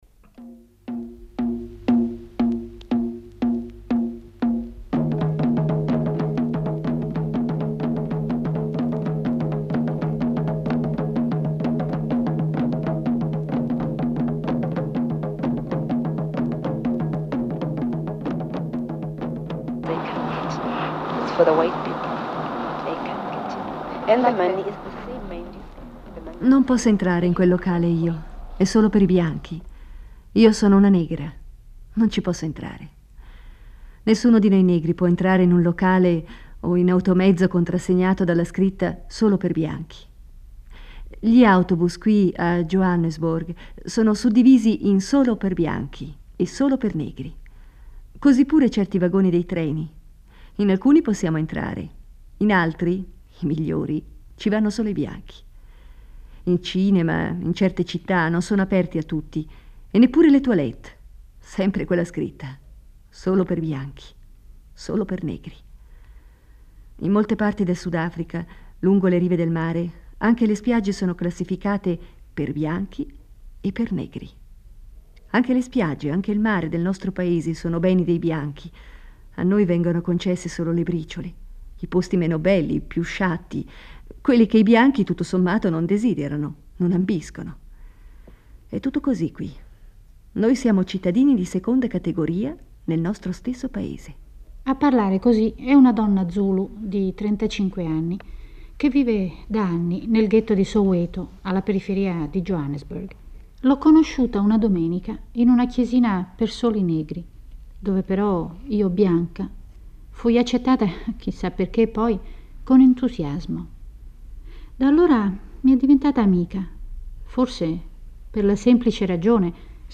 La giornata di una donna nera di colore in Sudafrica ai tempi dell'Apartheid. Un documentario radiofonico del 1980